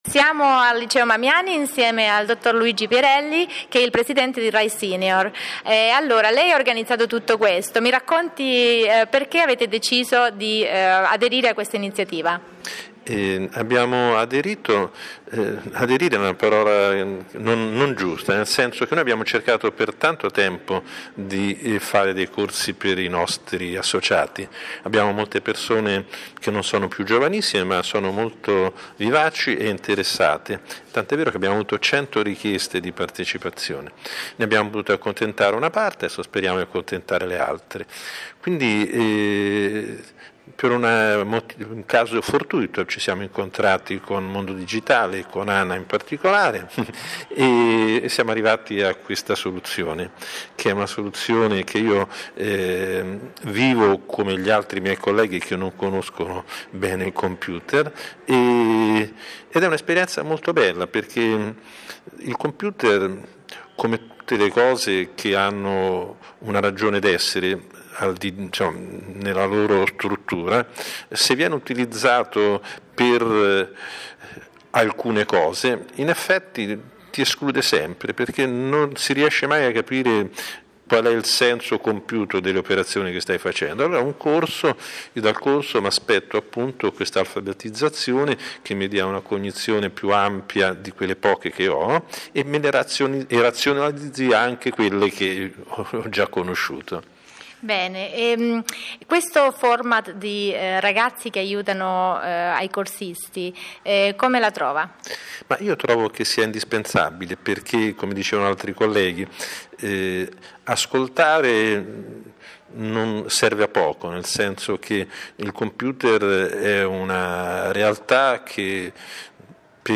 Intervista_di_gruppo.mp3